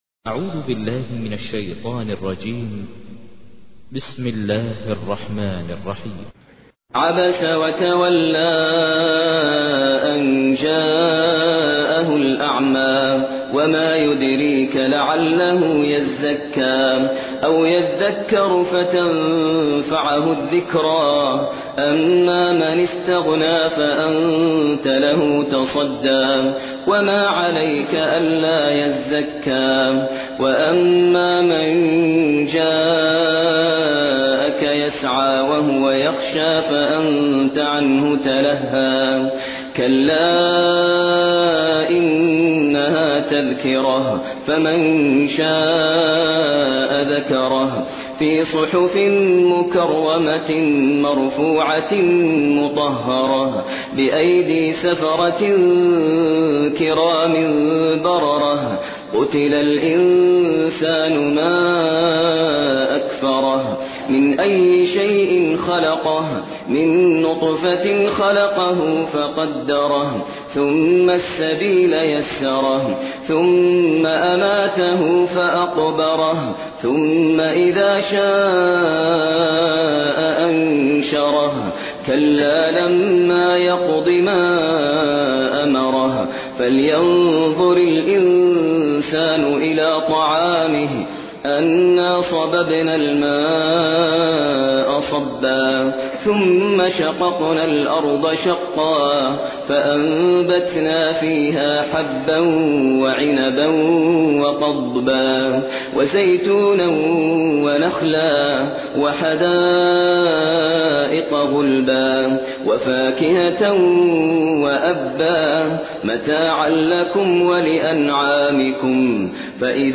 تحميل : 80. سورة عبس / القارئ ماهر المعيقلي / القرآن الكريم / موقع يا حسين